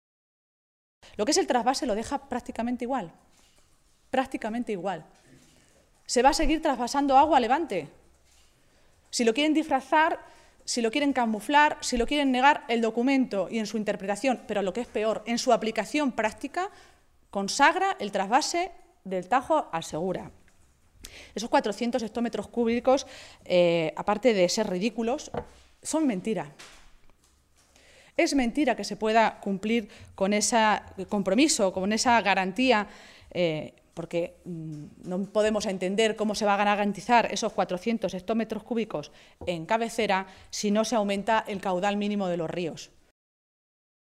Maestre hacía estas declaraciones en una comparecencia ante los medios de comunicación, en Toledo, en la que valoraba la propuesta de Plan Hidrológico del Tajo que ha publicado hoy en el Boletín Oficial del Estado el Ministerio de Agricultura.
Cortes de audio de la rueda de prensa